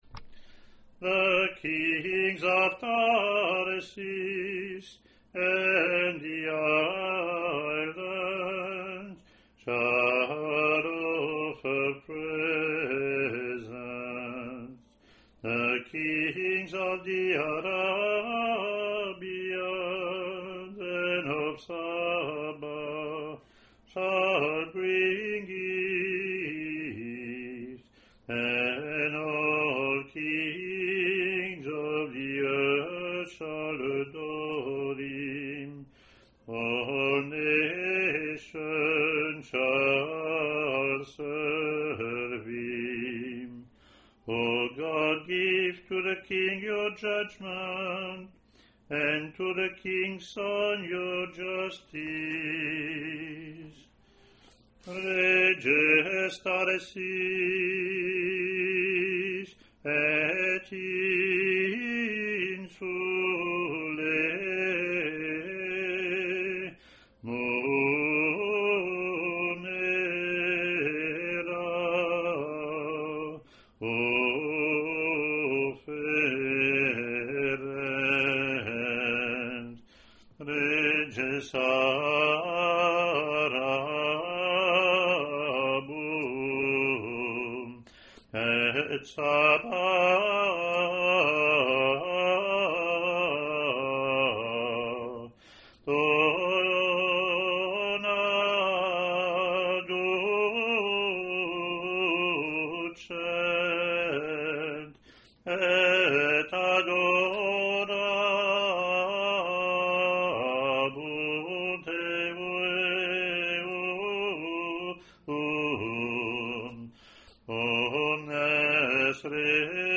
The Roman Missal gives us these Bible verses to be sung per the examples recorded: the congregation joins the cantor for the antiphon (printed), then the cantor sing the Psalm alone, then the congregation and cantor repeat the antiphon.
English antiphon – English verseLatin antiphon)